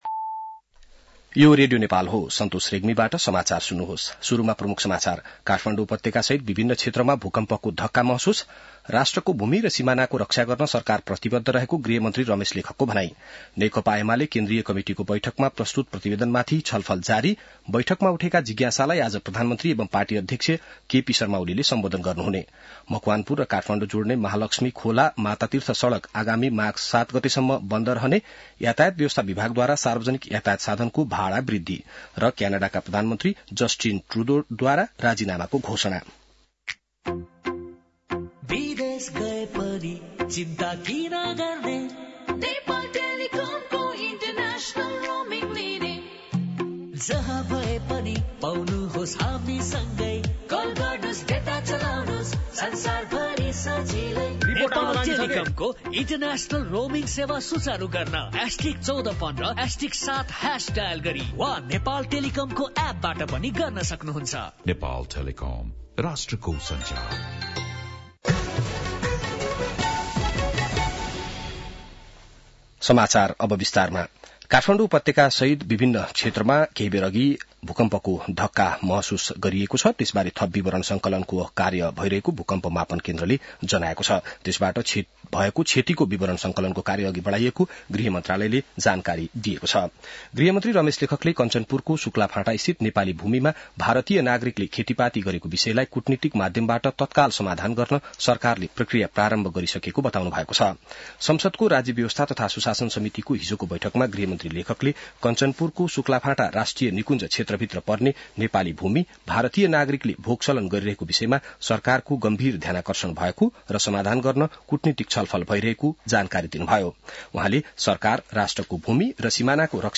An online outlet of Nepal's national radio broadcaster
बिहान ७ बजेको नेपाली समाचार : २४ पुष , २०८१